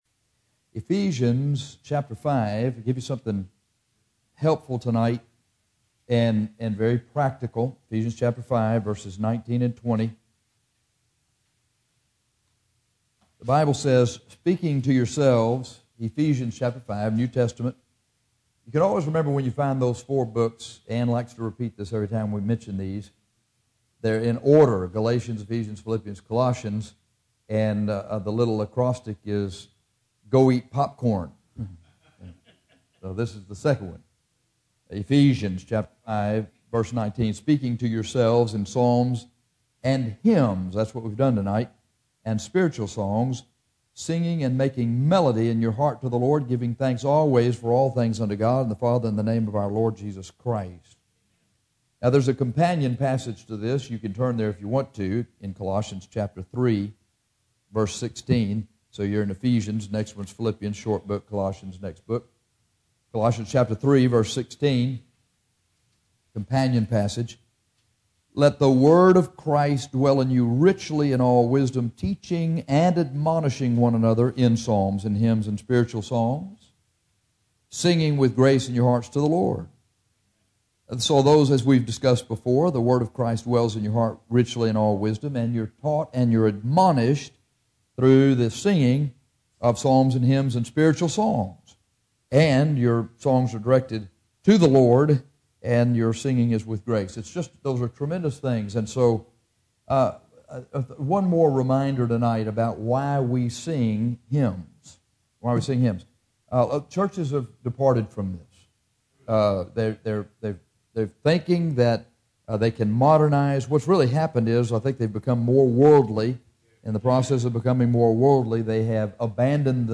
This is a sermon on why we sing hymns.